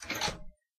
spring.ogg